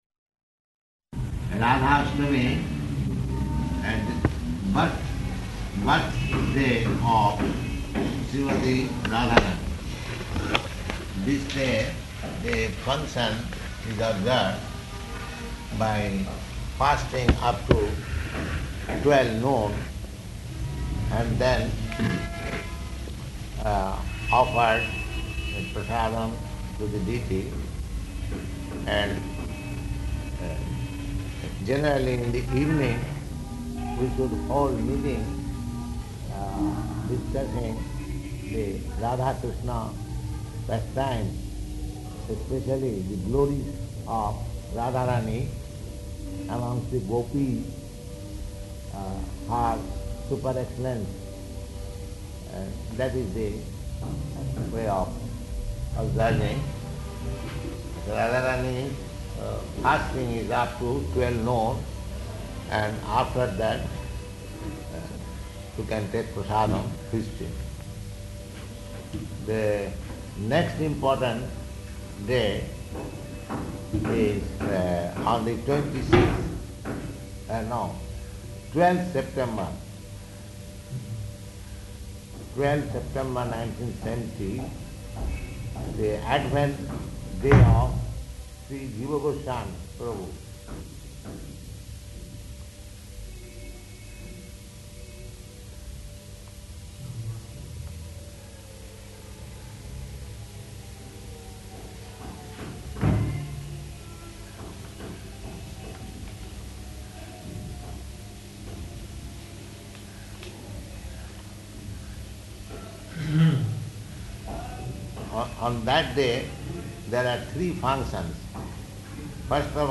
Vaiṣṇava Calendar Description --:-- --:-- Type: Conversation Dated: September 2nd 1970 Location: Calcutta Audio file: 700902R1-CALCUTTA.mp3 Prabhupāda: ...Rādhāṣṭami, and birth, birthday of Śrīmatī Rādhārāṇī.